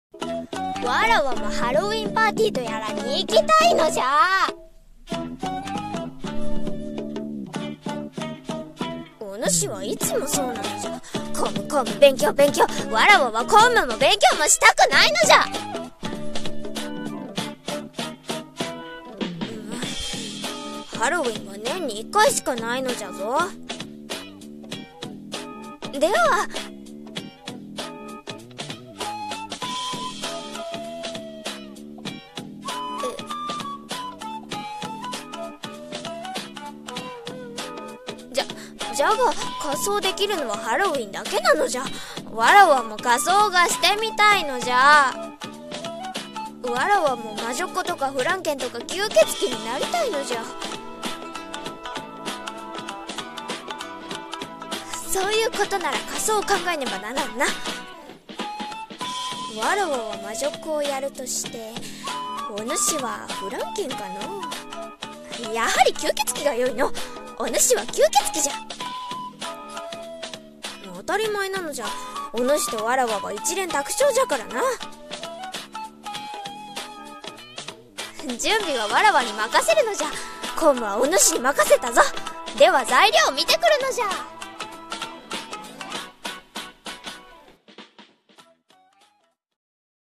声劇 掛け合い】姫様のハロウィン🎃